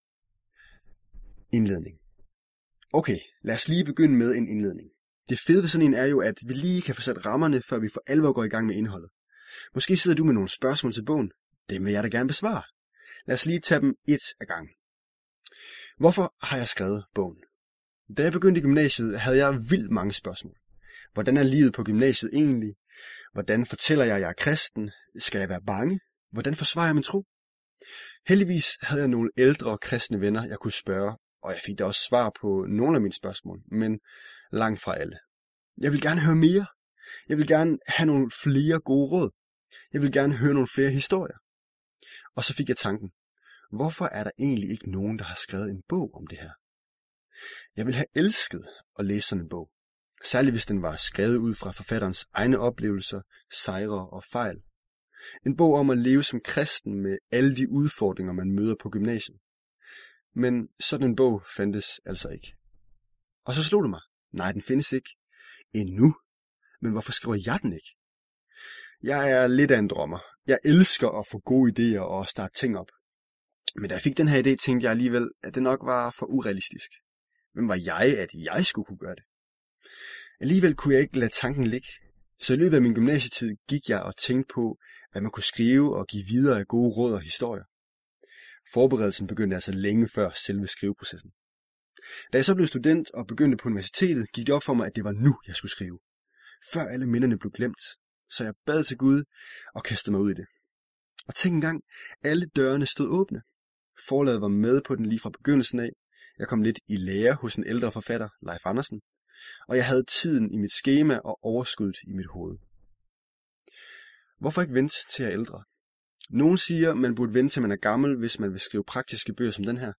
Gymnasietro - Forbliv kristen og gør en forskel. Lydbog til download som MP3-filer og M4B-filer til brug på Apple-produkter.
Hør et uddrag af Gymnasietro